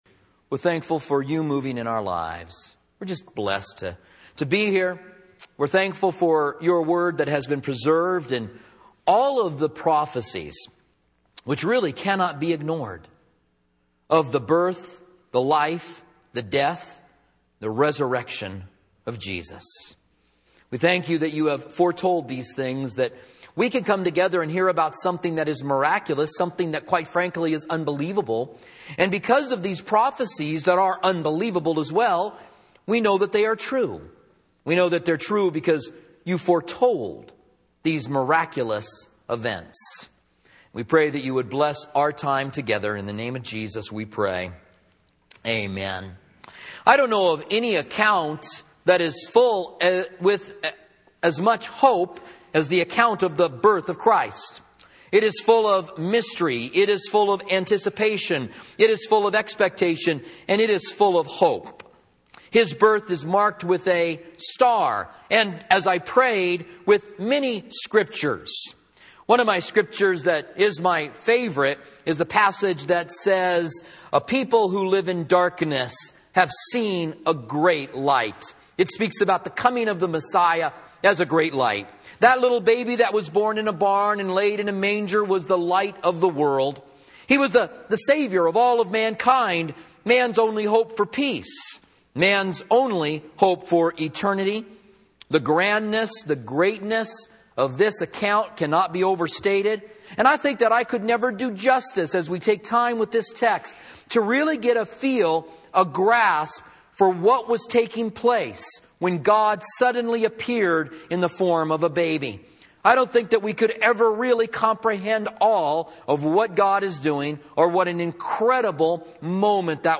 Christmas Holiday Message